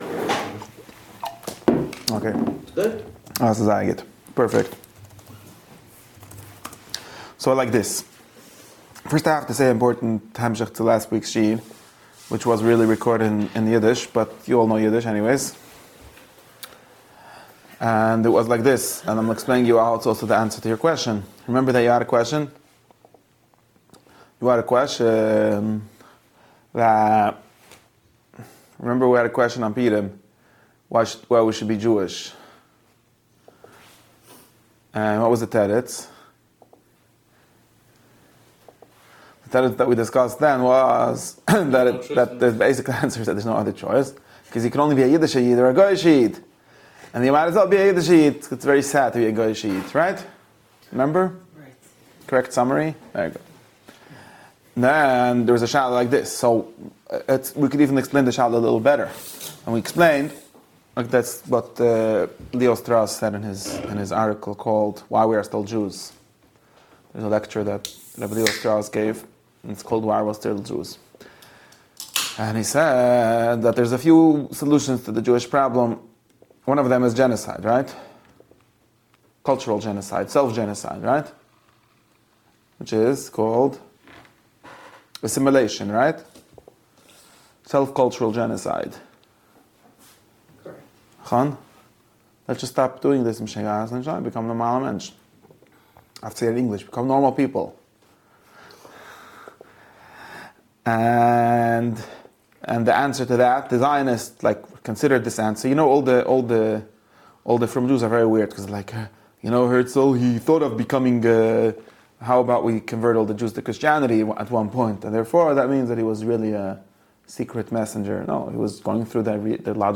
This lecture addresses the question of why Jews should remain Jewish rather than assimilate, examining Leo Strauss's argument that assimilation fails because one can only be a "Jewish Jew" or a "Gentile-ish Jew." The instructor challenges the counter-argument that multi-generational assimilation could eventually succeed, introducing the concept that parental influence naturally extends only four generations and exploring how Abraham's covenant and the Akeidah (binding of Isaac) represent a commitment to transcend this natural limit by accepting exile and suffering for the sake of a messianic future beyond one's great-grandchildren.